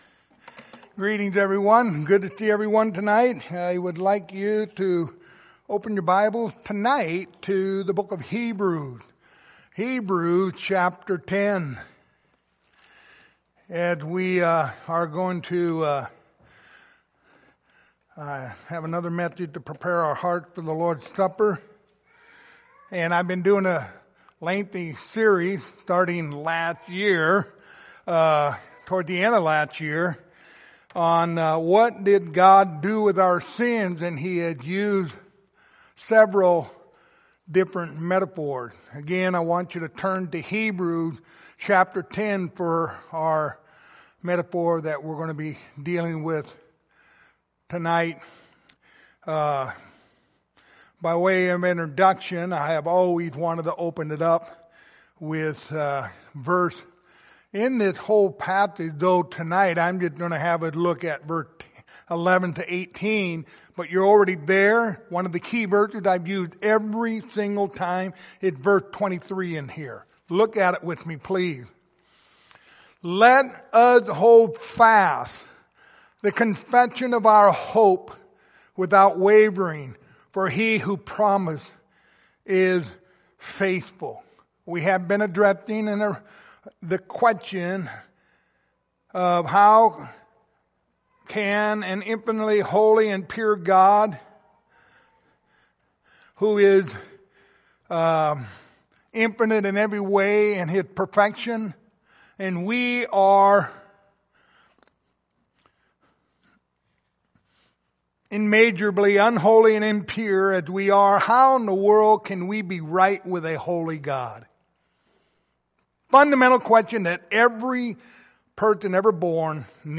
Lord's Supper Passage: Hebrews 10:11-18 Service Type: Lord's Supper Topics